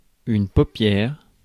Ääntäminen
IPA : /ˈaɪlɪd/